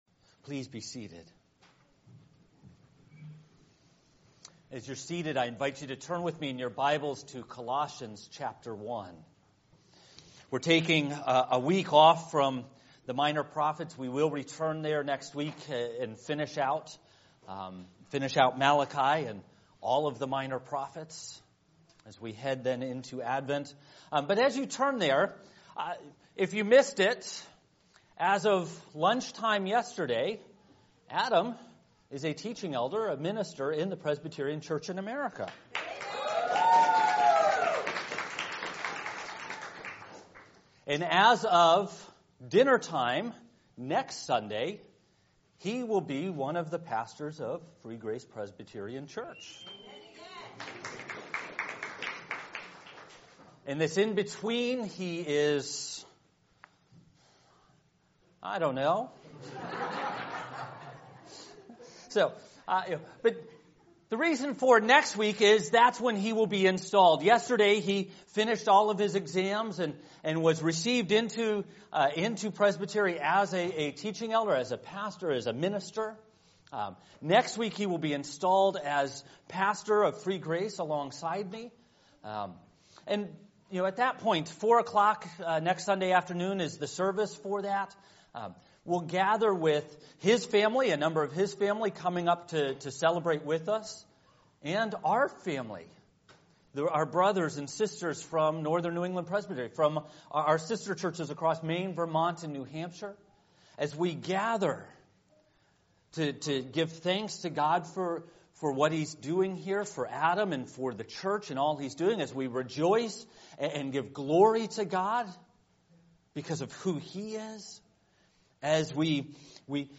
A Presbyterian Church (PCA) serving Lewiston and Auburn in Central Maine
sermon